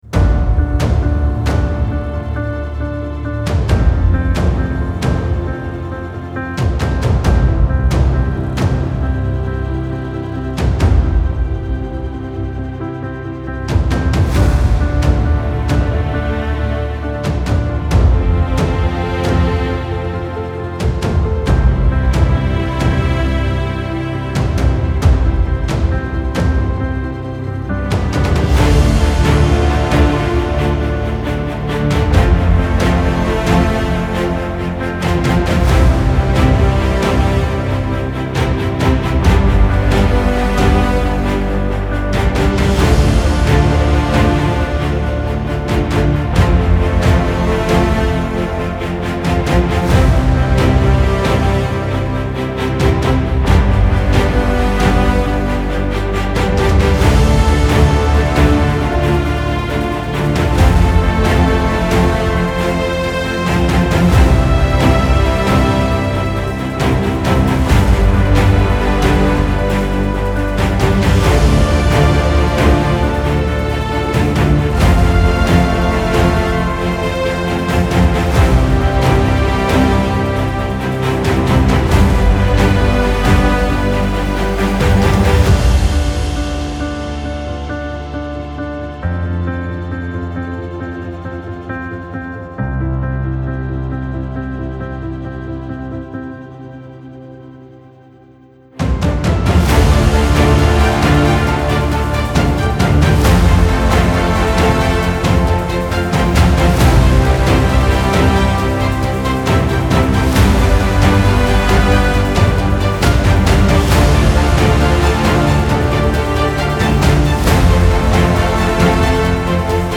سبک اپیک , موسیقی بی کلام